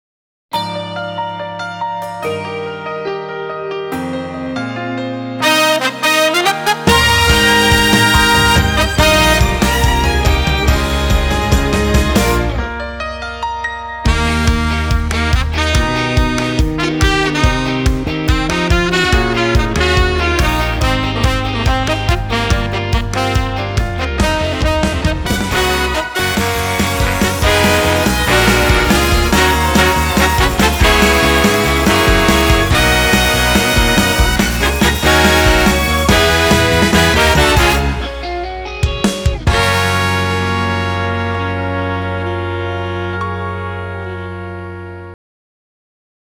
M.アニメ
難易度 初〜中 分類 駈足14２ 時間 4分24秒
編成内容 大太鼓、中太鼓、小太鼓、トリオ、シンバル 作成No 368